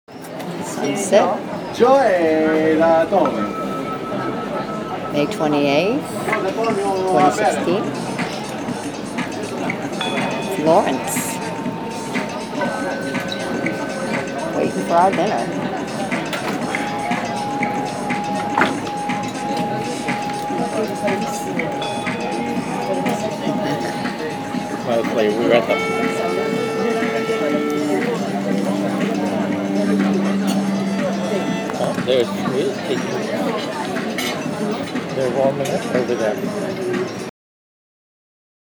May 28, 2016 Florence, Italy Santa Maria Novella Square in Florence at sunset- just before we sat down to dinner. Quiet sidewalk chatter to accompany the bells.